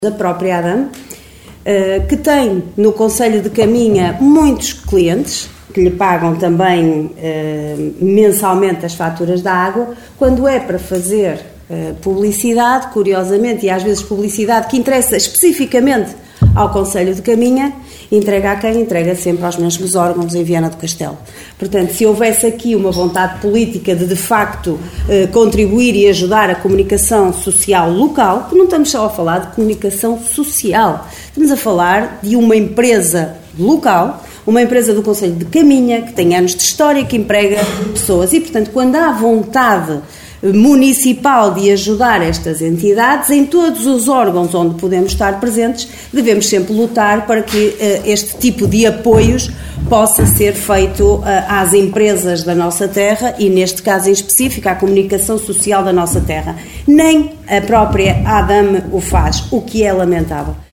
Excertos da última reunião de Câmara, realizada na passada quarta-feira no Salão Nobre dos Paços do Concelho, para contratação de 10 funcionários e atribuição de subsídios.